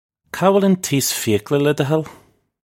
Kah will un teece fee-ok-la, leh duh hull? (U)
This is an approximate phonetic pronunciation of the phrase.